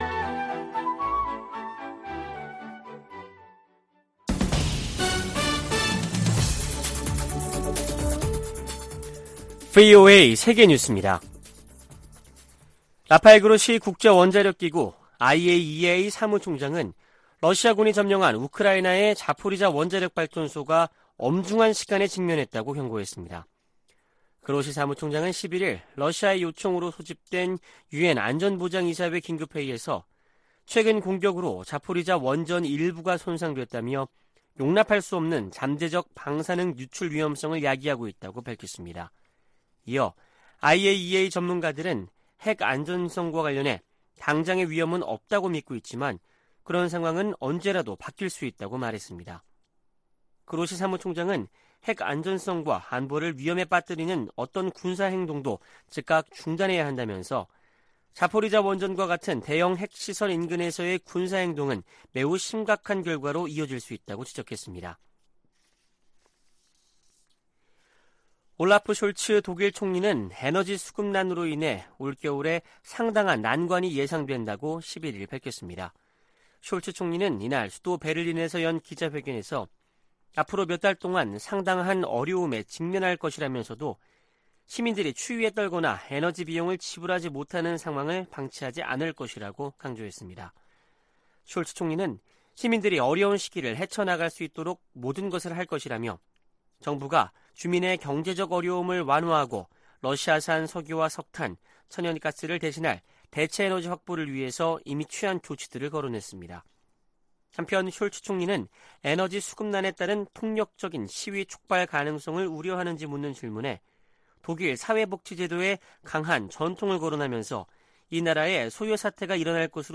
VOA 한국어 아침 뉴스 프로그램 '워싱턴 뉴스 광장' 2022년 8월 13일 방송입니다. 방한한 안토니우 구테흐스 유엔 사무총장은 북한의 완전하고 검증 가능하며 불가역적인 비핵화를 지지한다고 밝혔습니다.